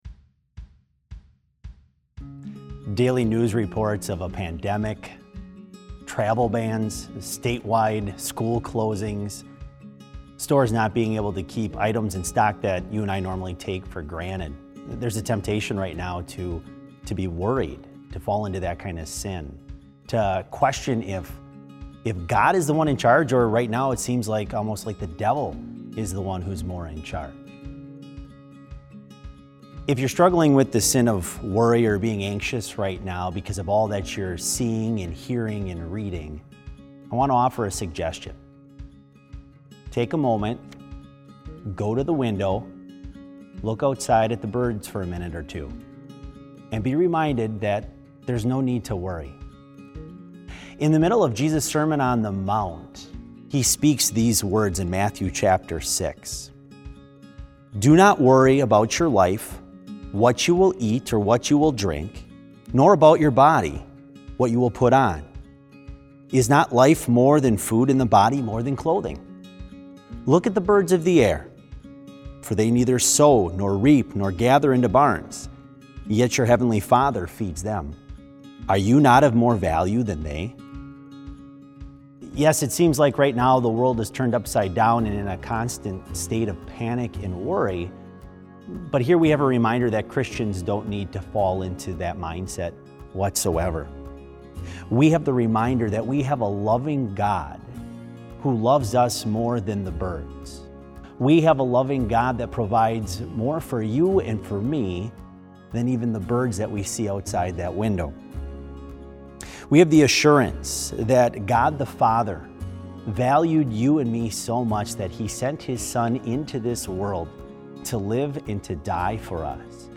Complete service audio for BLC Devotion - March 20, 2020